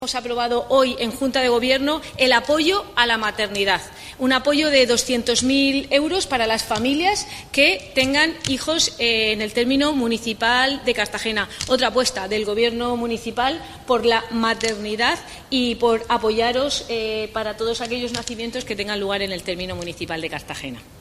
Enlace a Declaraciones de la concejal Cristina Mora sobre ayudas a la natalidad y la maternidad